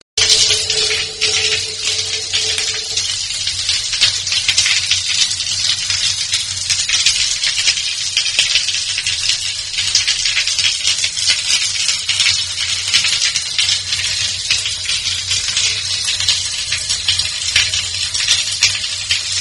Звук воды из душа льется на металлический поддон